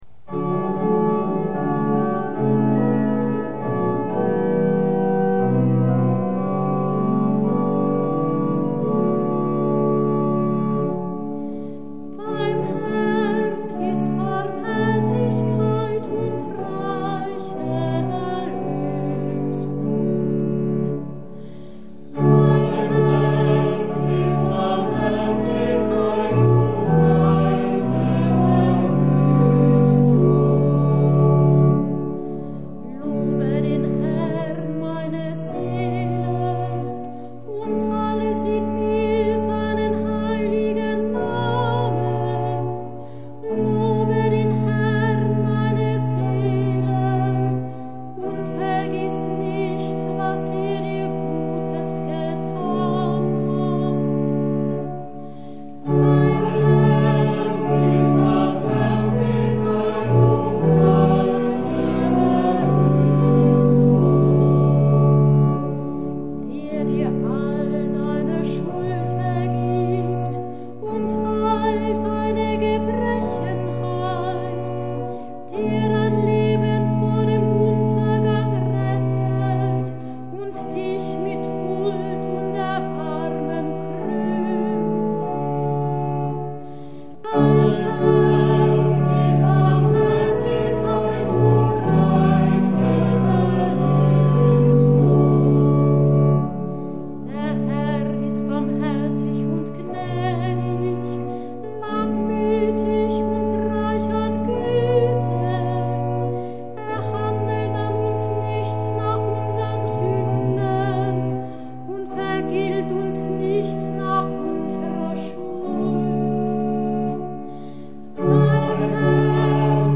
Hörbeispiele aus Kantorenbüchern und eine Auswahl aus dem Gurker Psalter
Kehrverse im neuen Gotteslob